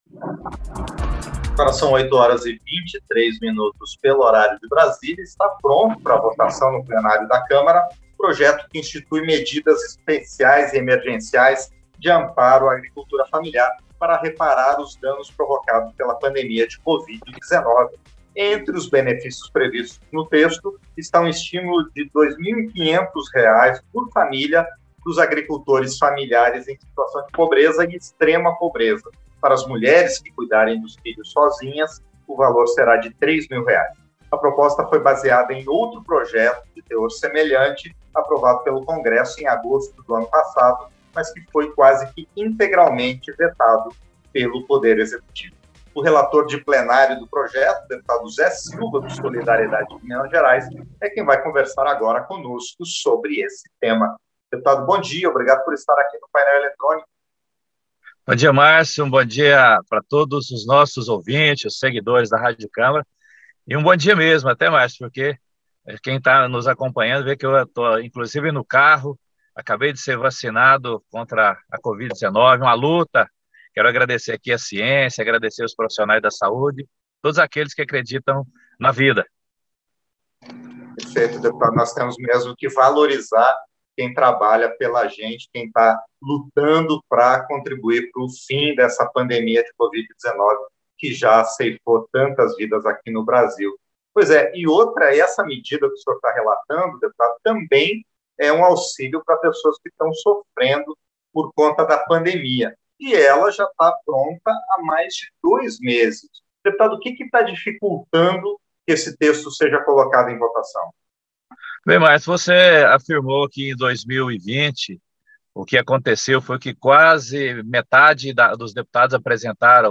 Entrevista - Dep. Zé Silva (SD-MG)